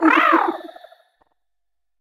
Cri de Piétacé dans Pokémon HOME.